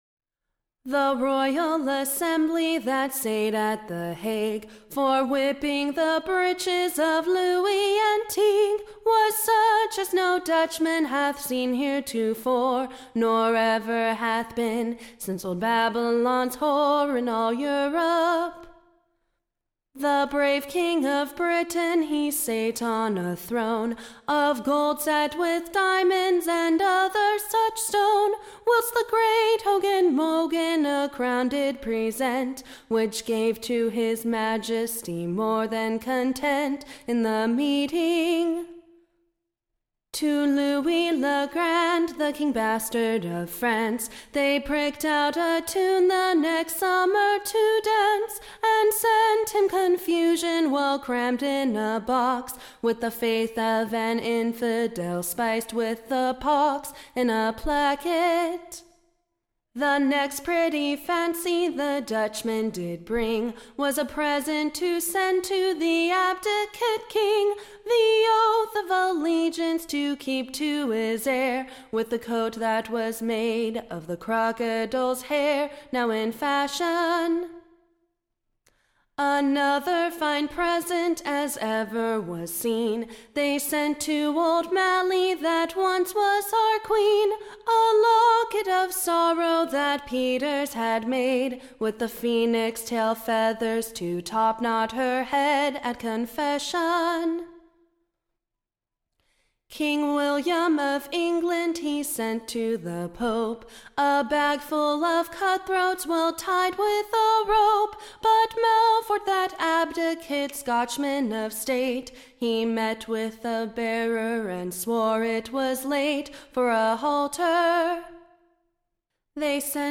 Being an Excellent new Song